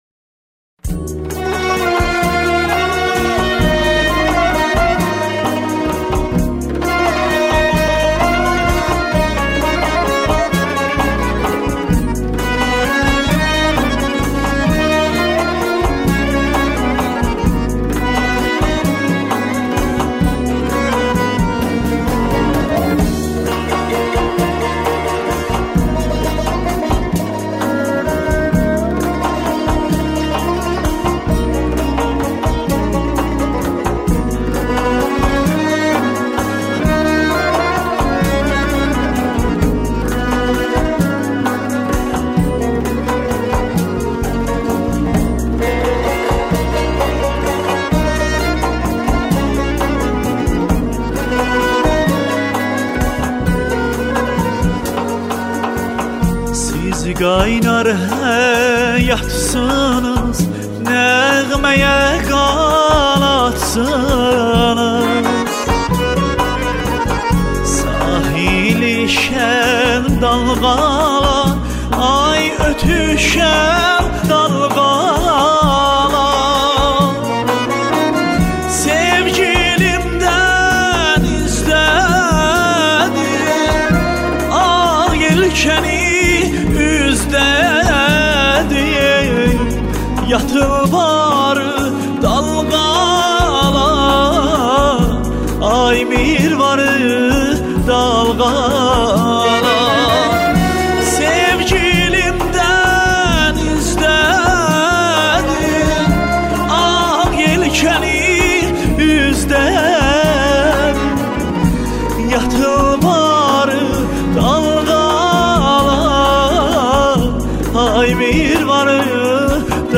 موسیقی آذری